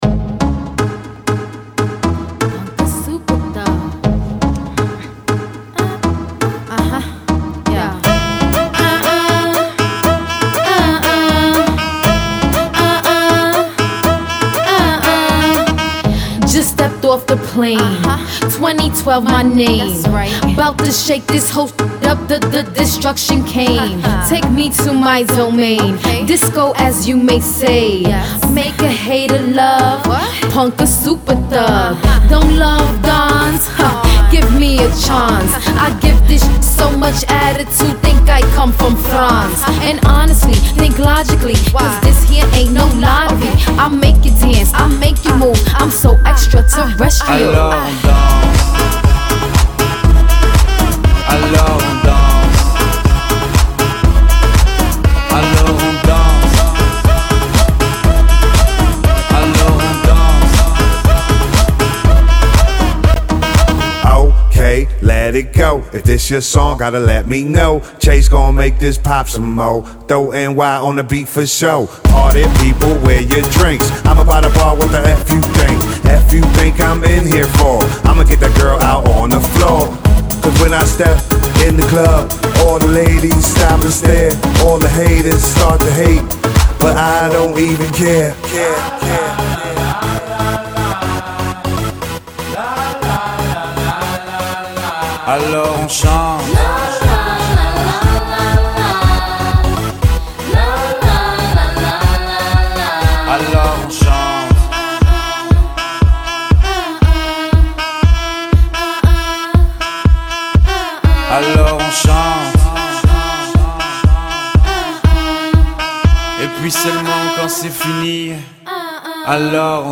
Hip Hop Pop